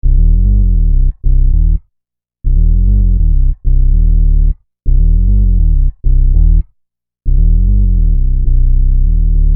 Bass 01.wav